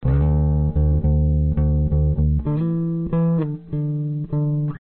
电贝司图
描述：D大调、Amaj、G大调贝斯图在芬德精密贝斯吉他上的应用
Tag: 贝司 芬德 精密